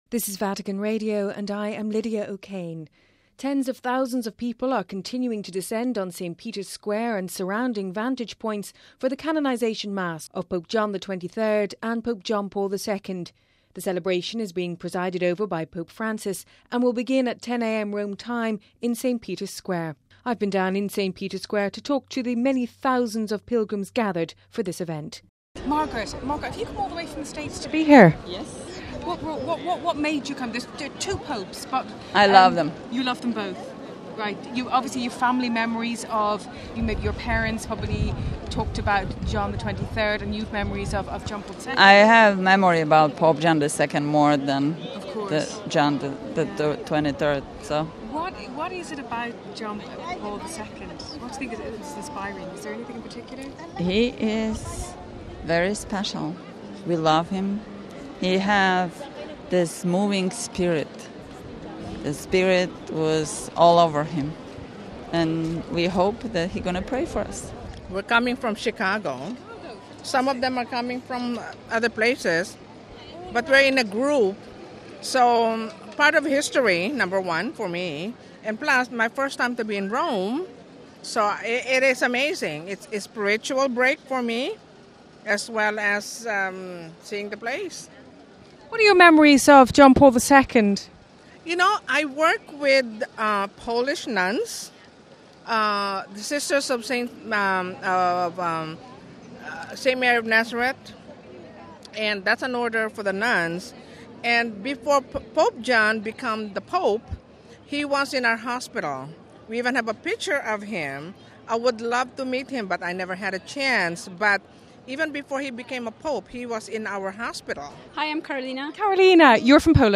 All through the night and into the early hours of this morning people from all around the world have been holding prayer vigils, playing music and soaking up the atmosphere of this unique event.